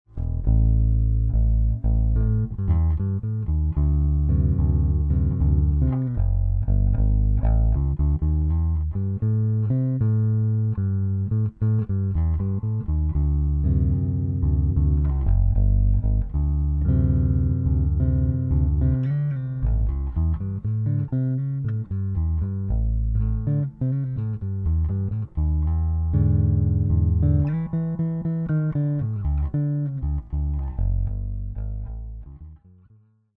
On that fly guitars web site they had sound clips for a 400.